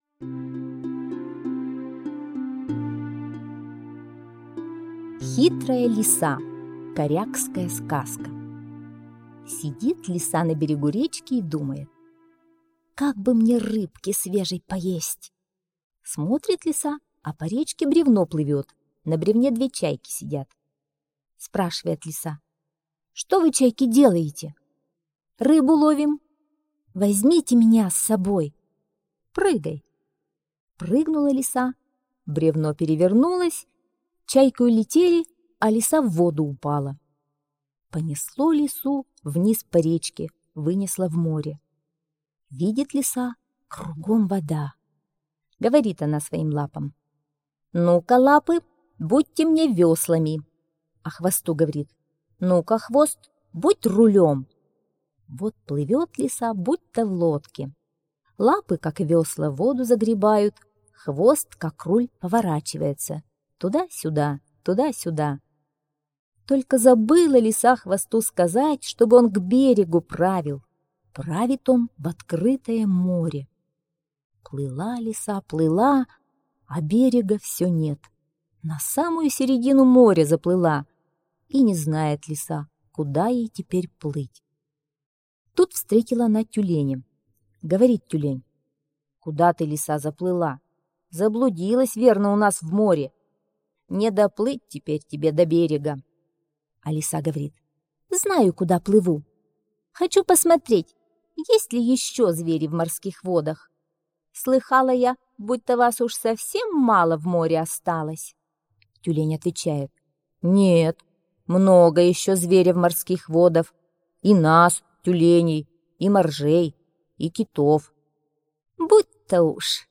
Аудиосказка «Хитрая лиса»